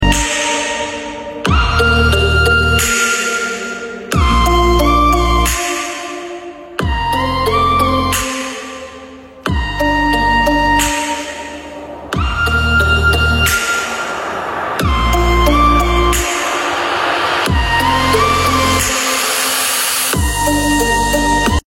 Three-way subwoofer Rated 200 watts of high power